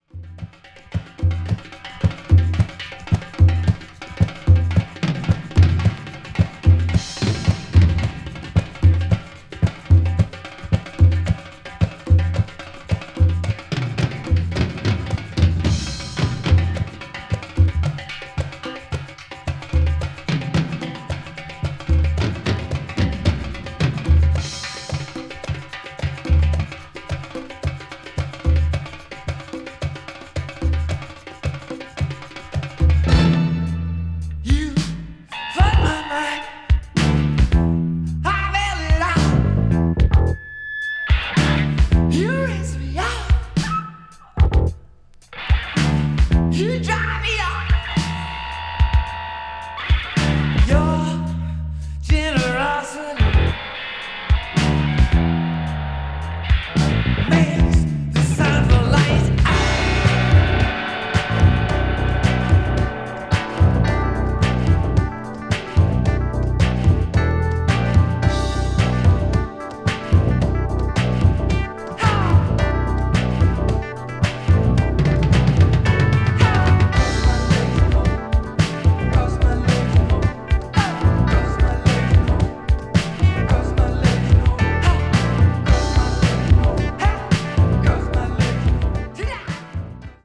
フロア・ユースのダンサブルなトラックも断然カッコいいですよ。